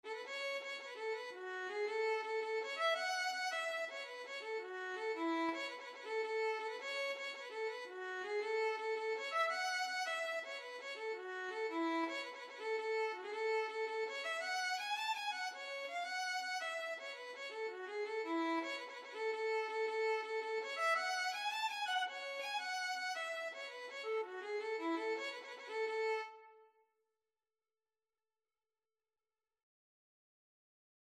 9/8 (View more 9/8 Music)
E5-A6
A major (Sounding Pitch) (View more A major Music for Violin )
Violin  (View more Intermediate Violin Music)
Traditional (View more Traditional Violin Music)
Irish